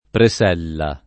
preS$lla] s. f. — dim. di presa in vari sign., tra cui più com. quello di «appezzamento di terreno» (da cui il v. appresellare) — nel sign. di «cianfrino (scalpello)» (da cui il v. presellare), anche presello [preS$llo] s. m.